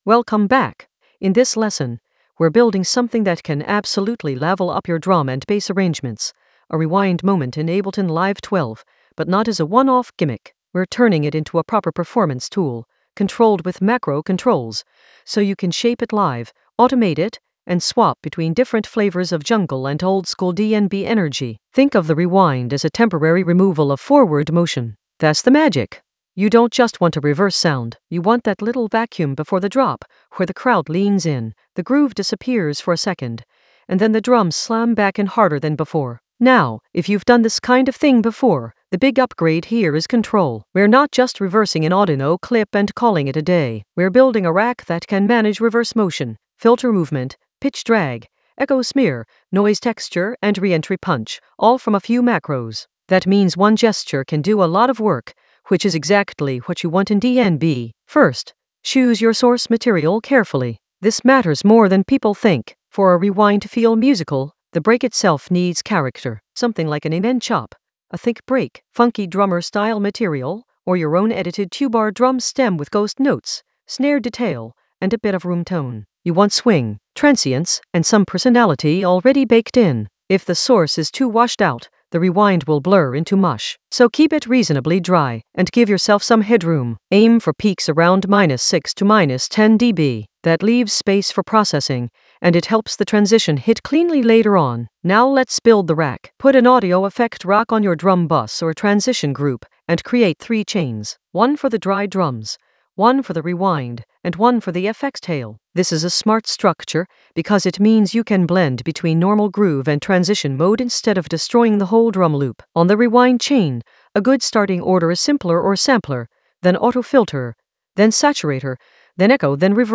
An AI-generated advanced Ableton lesson focused on Rewind moment in Ableton Live 12: push it using macro controls creatively for jungle oldskool DnB vibes in the Drums area of drum and bass production.
Narrated lesson audio
The voice track includes the tutorial plus extra teacher commentary.